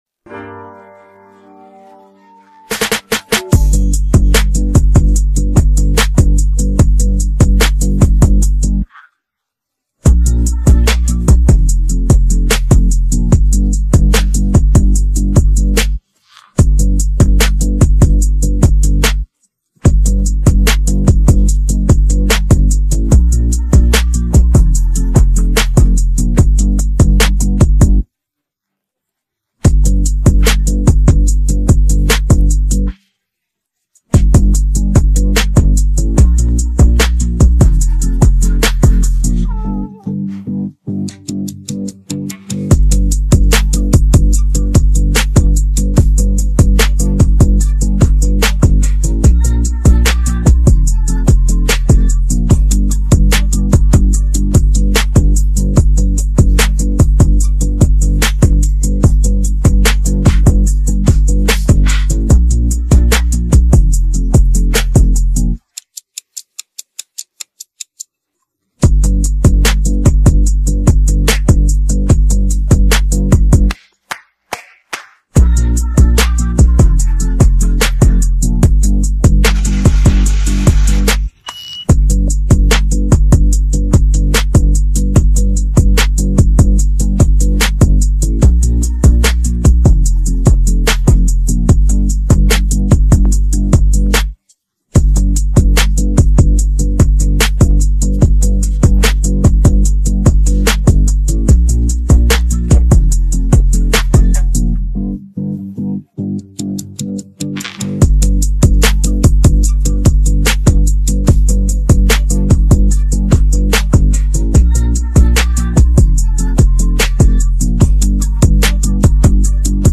This is the instrumental of the new song.